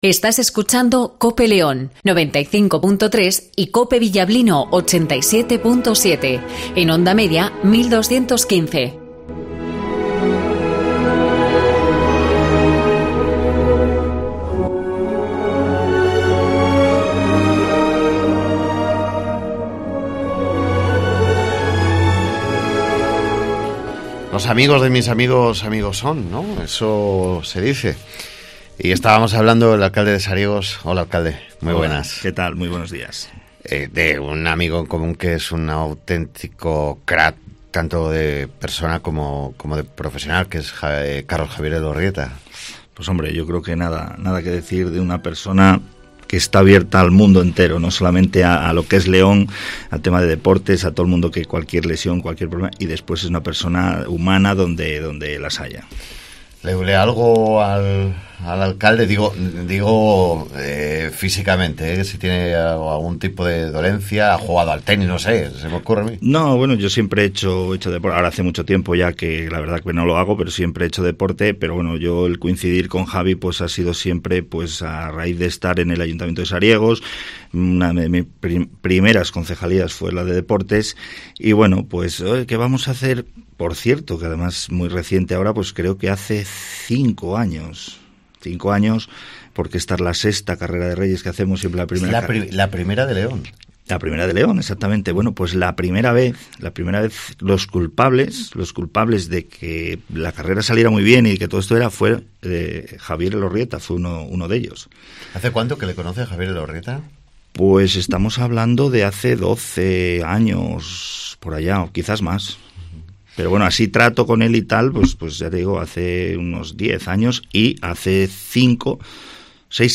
Entrevista Roberto Aller Llanos - Alcalde de Sariegos ( 03-01-19 )